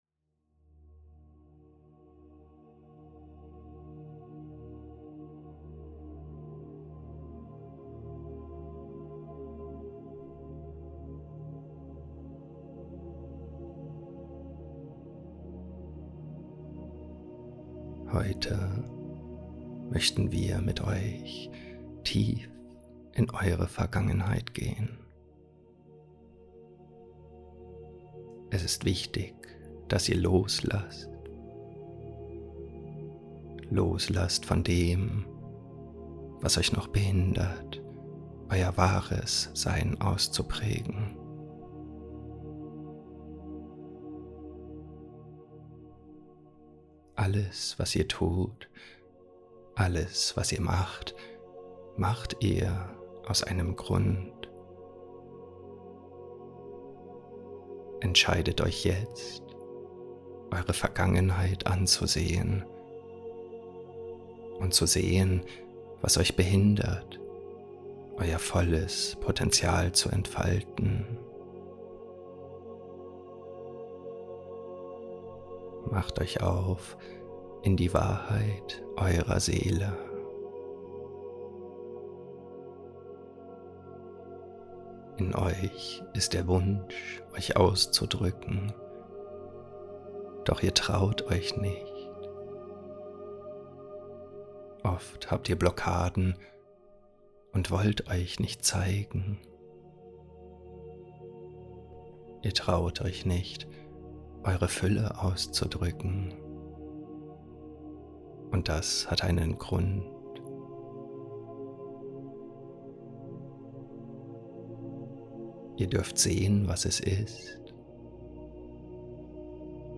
Kapitulation der Dunkelheit in Dir - Meditation - Licht-Akademie